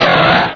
pokeemerald / sound / direct_sound_samples / cries / latias.aif
-Replaced the Gen. 1 to 3 cries with BW2 rips.